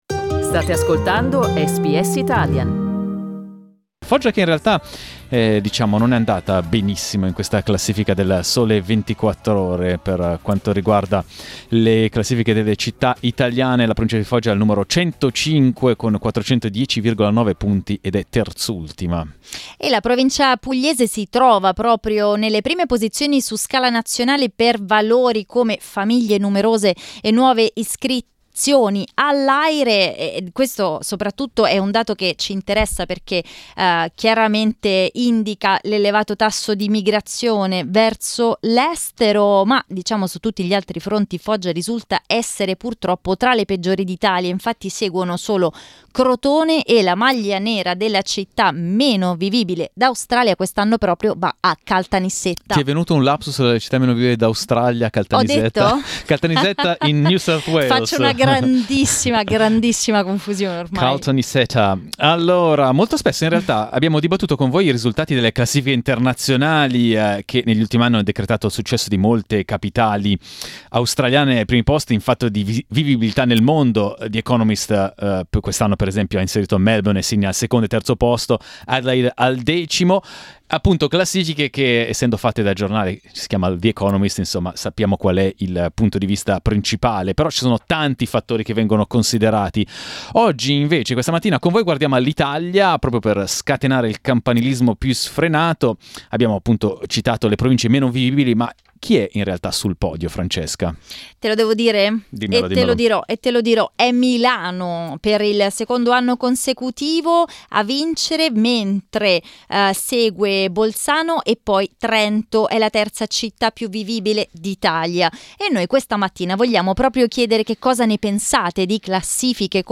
We asked SBS Italian listeners which place they would choose to live, if they returned to Italy. This is the result of our talk-back.